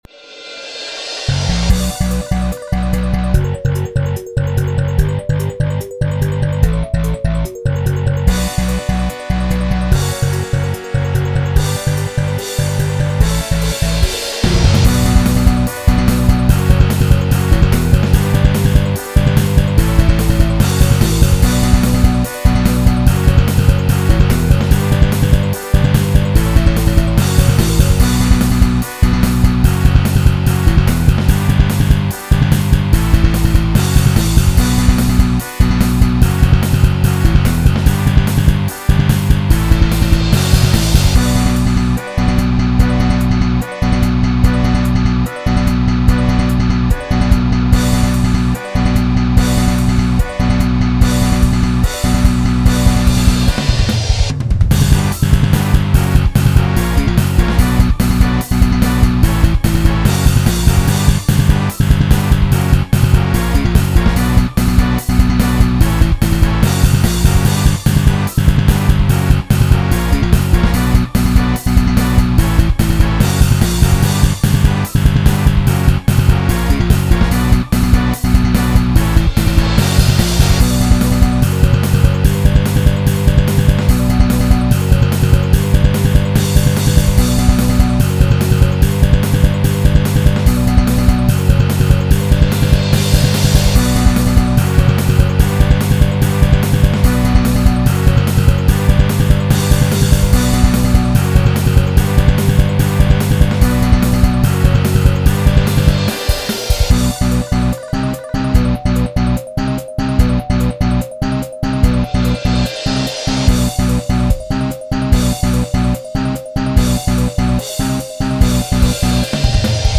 Music / Rock